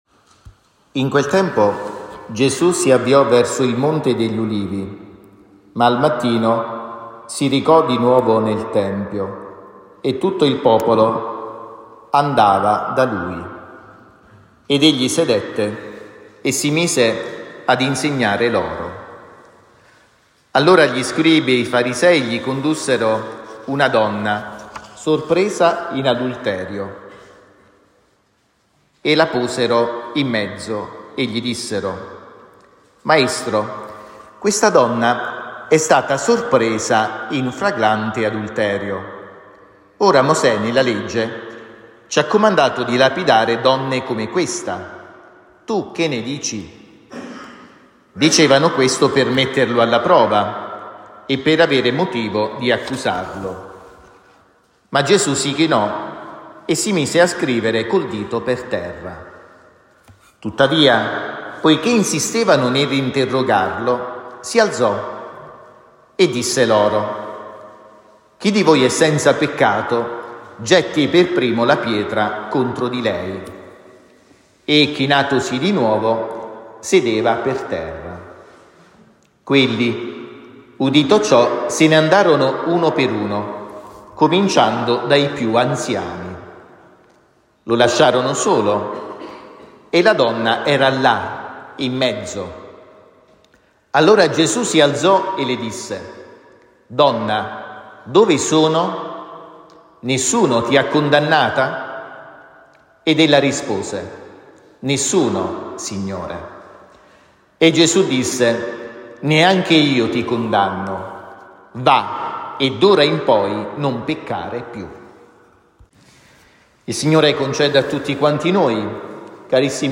omelia-6-aprile-2025.mp3